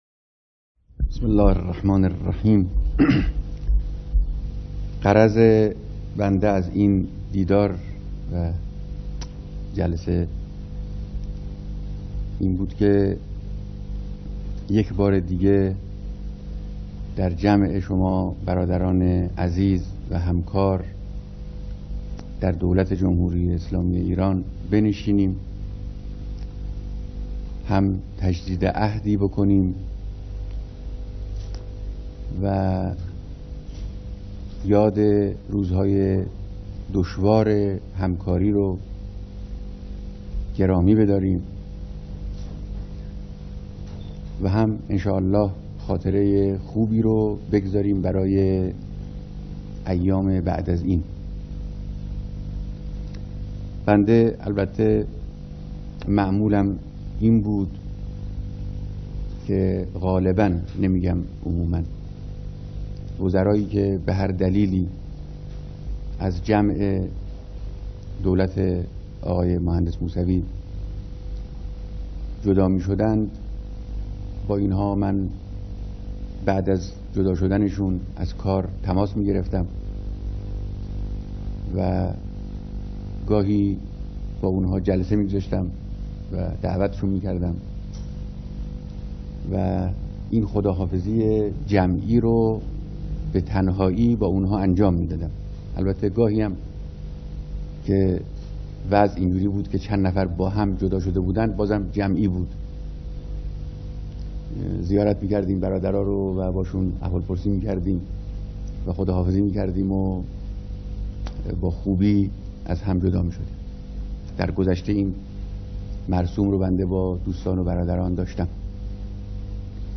بیانات رهبر انقلاب در مراسم تودیع اعضای هیأت دولت‌